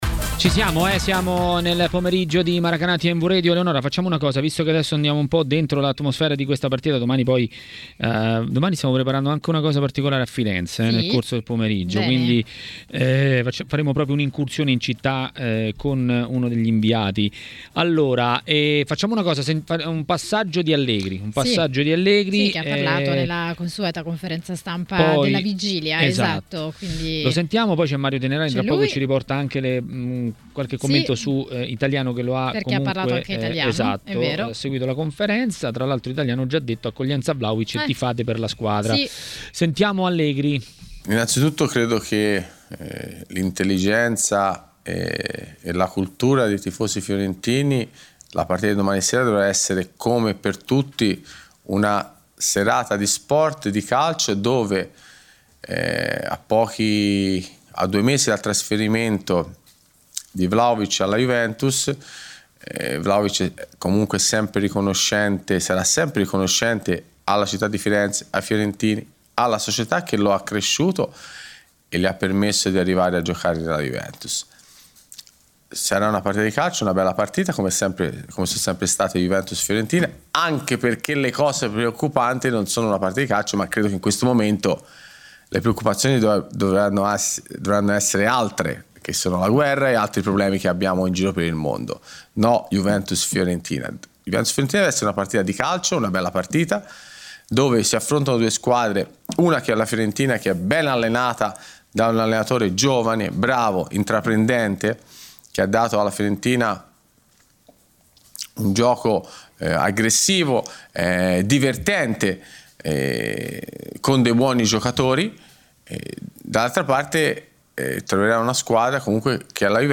nel pomeriggio di TMW Radio